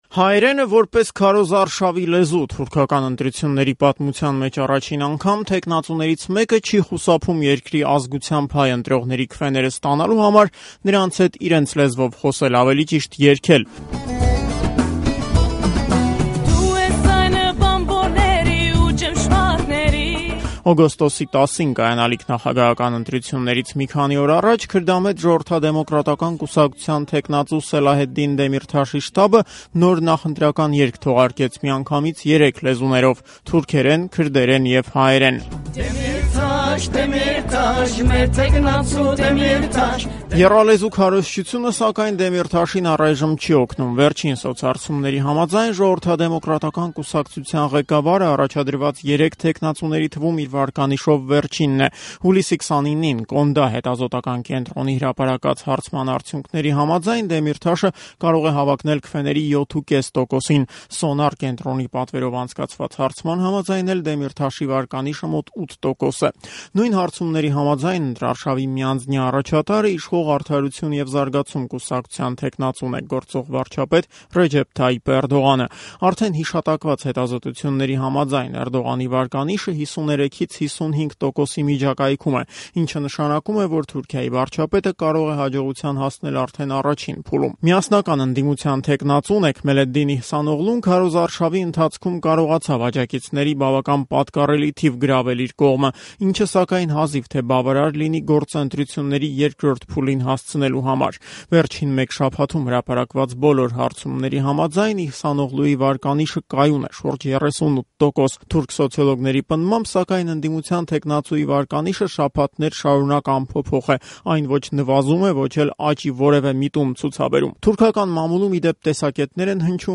Թուրքիայի նախագահի թեկնածուն հայերեն է երգում